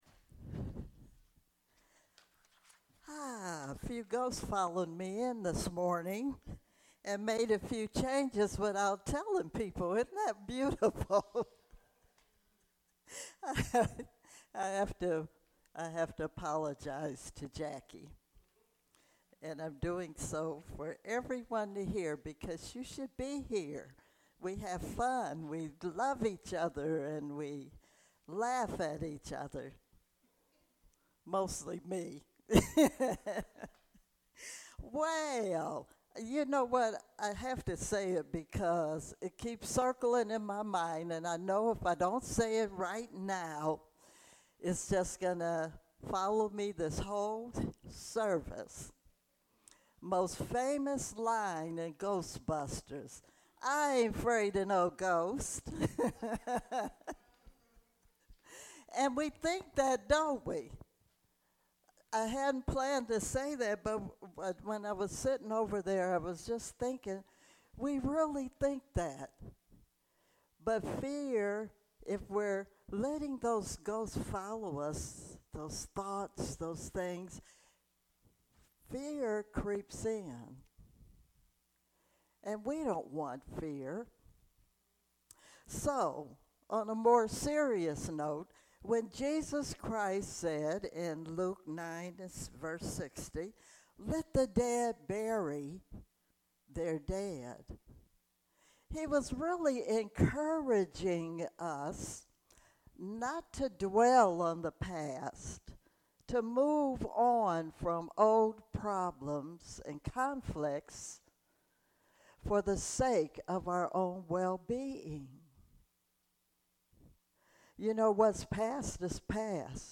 Series: Sermons 2022